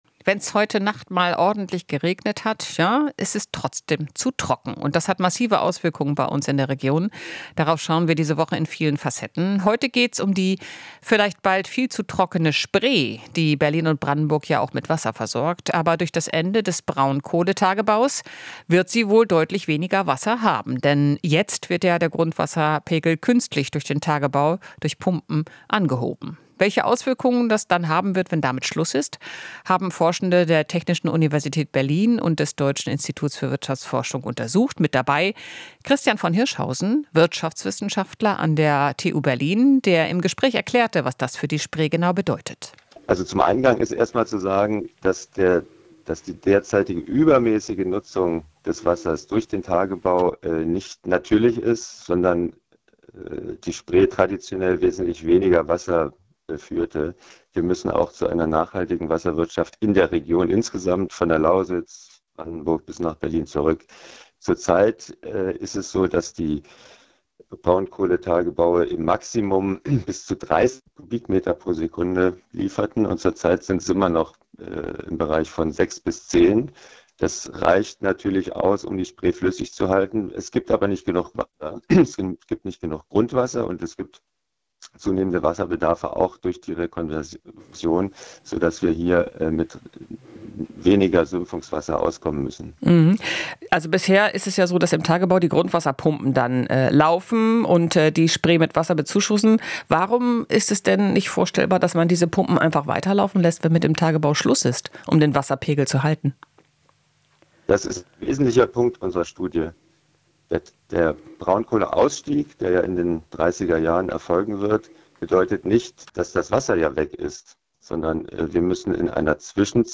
Interview - Weniger Wasser durch Kohleausstieg: Wie kann der Spree geholfen werden?